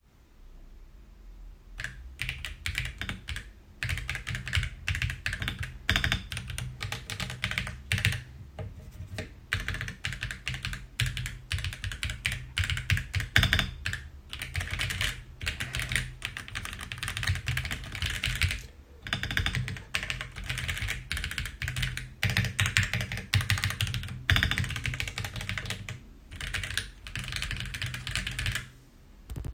Typ klawiatury Mechaniczna
Switche i dźwięki
Nie ma tego irytującego, pustego rezonansu, który często słychać w tańszych klawiaturach. Dzięki wytrzymałej obudowie i przemyślanym rozwiązaniom w środku, Uranus Pro brzmi dość cicho i przyjemnie.
Spacja, shift – wszystkie większe klawisze chodzą równo i bez żadnych niepożądanych grzechotań.
redragon-uranus-pro-pograne-probka-dziweku.mp3